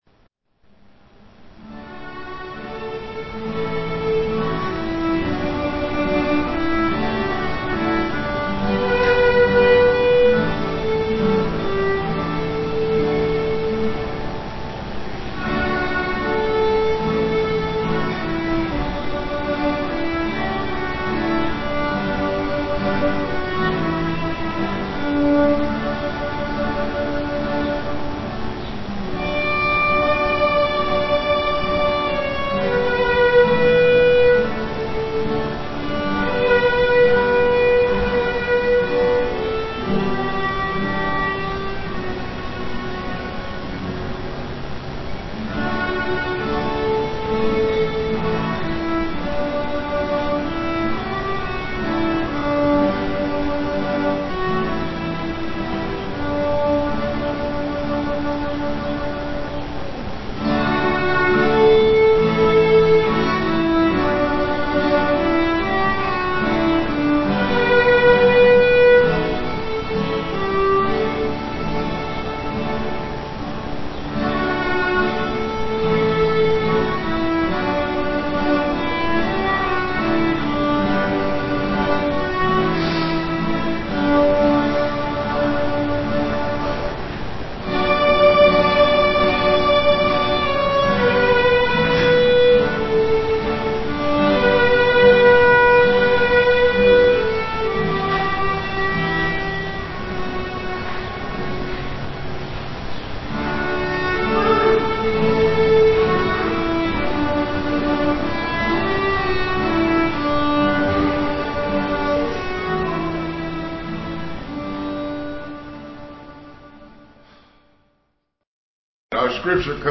Scripture: 1 Samuel 17, excerpts read
violin and guitar duet
Piano and organ offertory